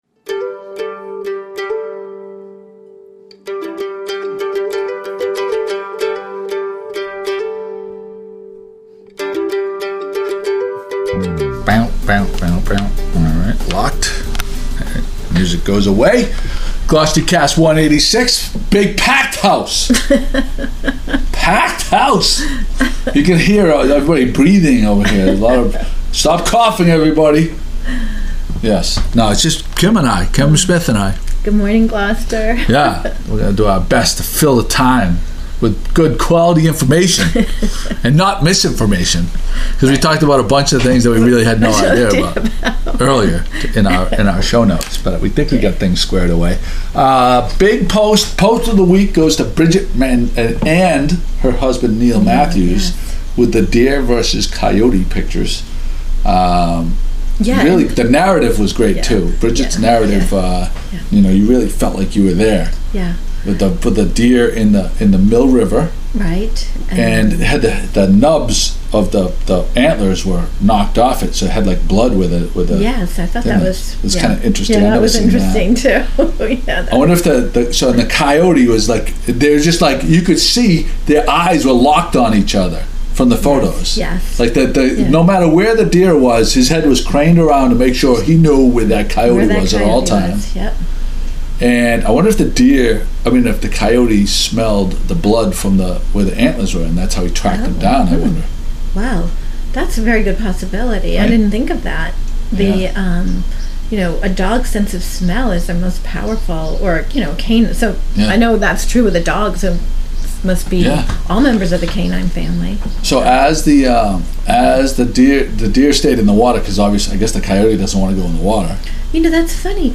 Gloucestercast 144 Taped Live At The Cape Ann Farmer’s Market